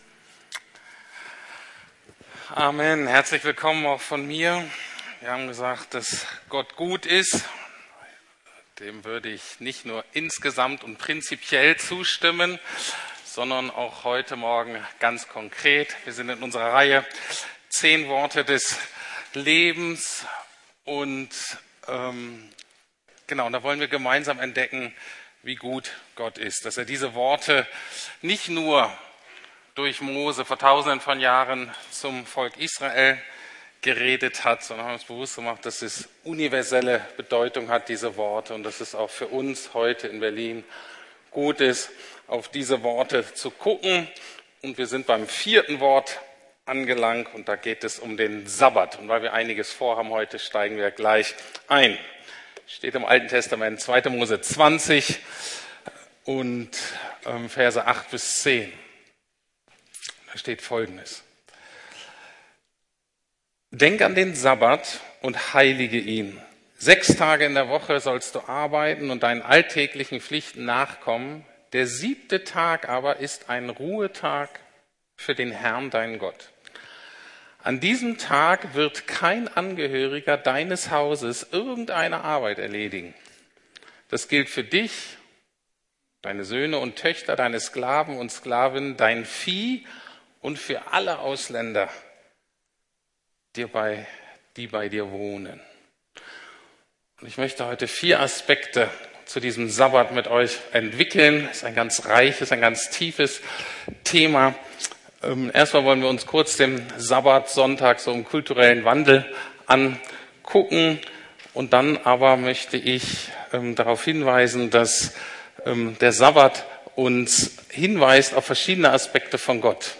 10 Worte des Lebens (Teil 4) ~ Predigten der LUKAS GEMEINDE Podcast